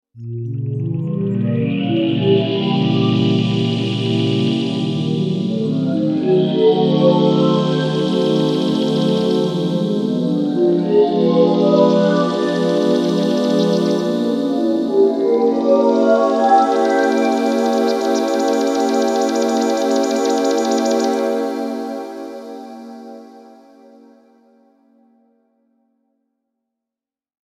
Eerie Magical Transformation Sound Effect
Perfect for spooky, fantasy, and cinematic scenes.
Genres: Sound Effects
Eerie-magical-transformation-sound-effect.mp3